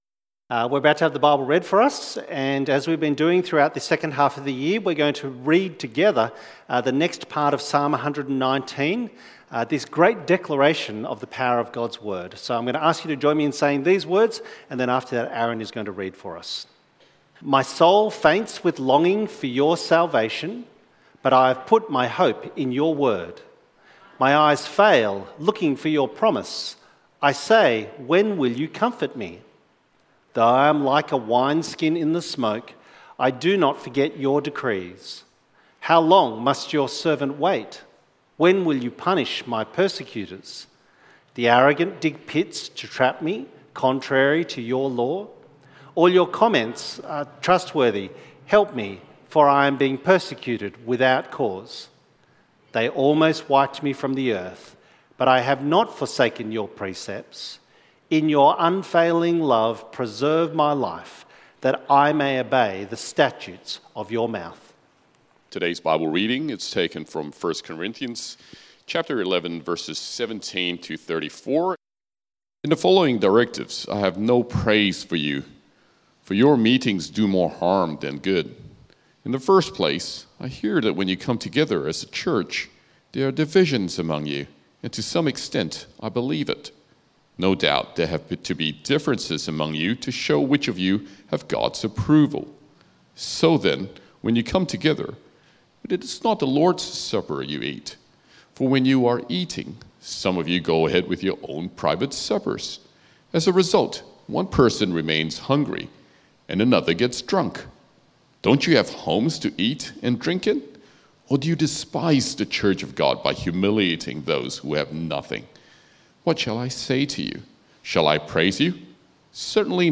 Thinking Theologically About Communion Sermon outline